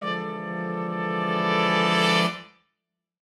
Index of /musicradar/gangster-sting-samples/Chord Hits/Horn Swells
GS_HornSwell-E7b2sus4.wav